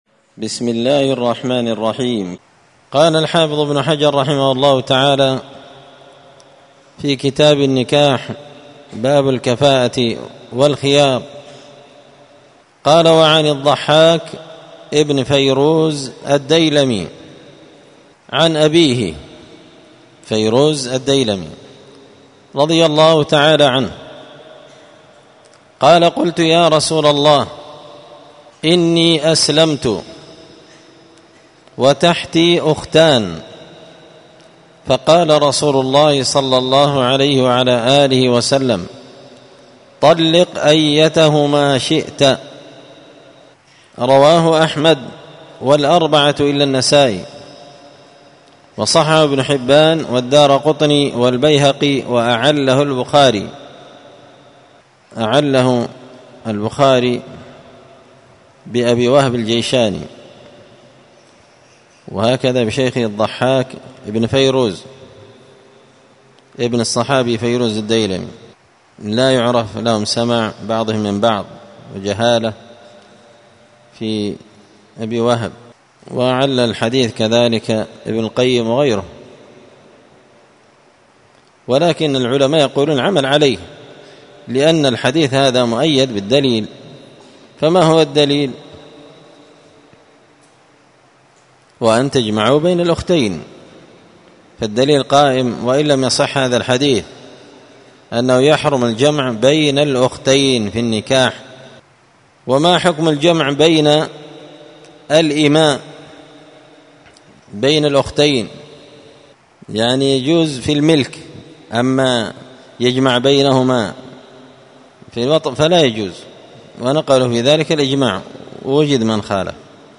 الدرس 17 تابع أحكام النكاح {باب الكفاءة والخيار}